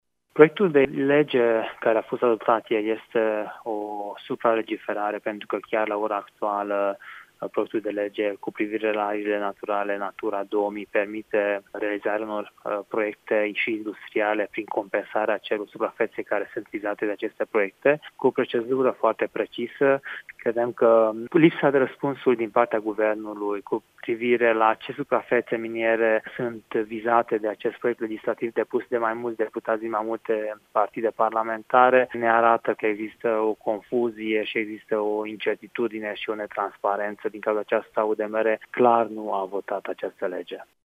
Deputatul UDMR, Korodi Attila, a declarat pentru RTM că formațiunea pe care o reprezintă nu a susținut legea: